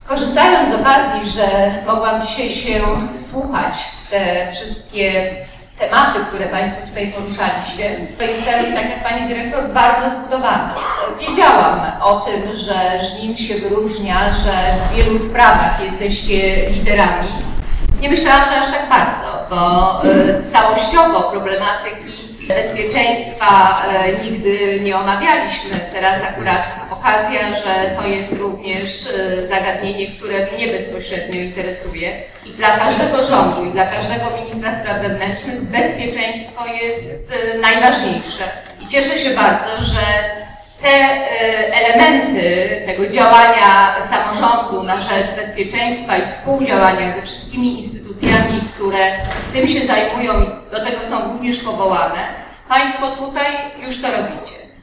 W niedzielę odbyła się w Żninie konferencja pod tytułem "Bezpieczna Gmina".
Gościem specjalnym debaty o bezpieczeństwie w gminie Żnin była Minister Spraw Wewnętrznych Teresa Piotrowska, która chwaliła samorząd lokalny oraz podległe mu jednostki za sprawne działanie w kierunku bezpieczeństwa.